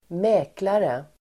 Ladda ner uttalet
Uttal: [²m'ä:klare]